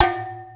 7. 07. Percussive FX 06 ZG